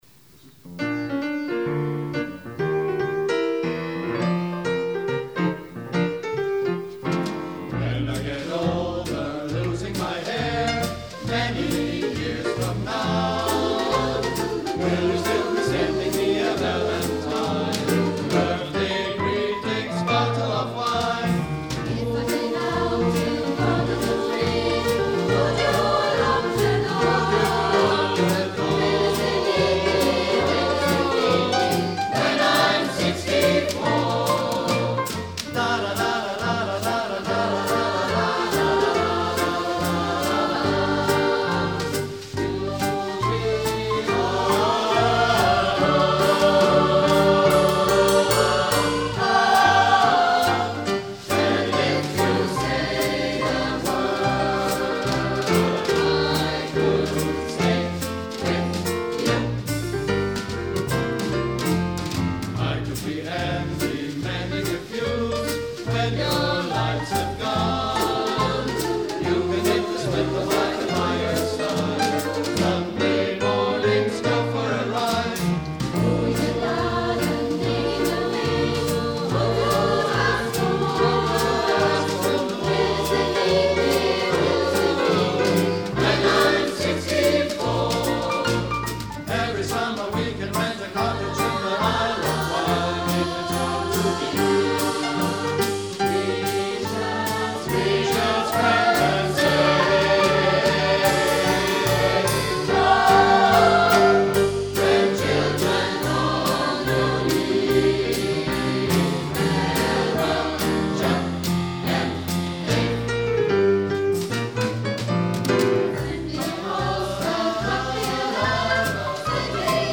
Am 18. Oktober 2024 fand um 19:30 im Festsaal der Arbeiterkammer das große HERBSTKONZERT statt.
Ein Rhythmusensemble sorgte zusätzlich für so manchen Schwung und Überraschung.